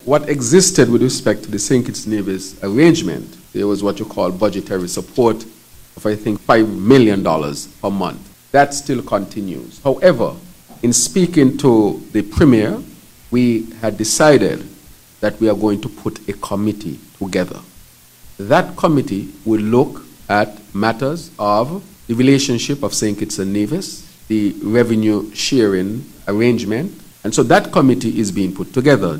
This question was posed to the Prime Minister during his first Press Conference for the year alongside the rest of the federal cabinet on January 18th, 2023.
Here is Prime Minister Drew: